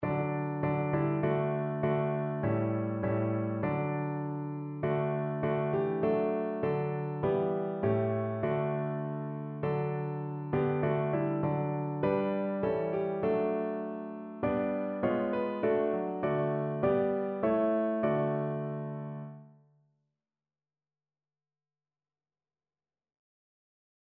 Größe und Macht Gottes Kinderlied Schöpfung Gottes
Notensatz (4 Stimmen gemischt)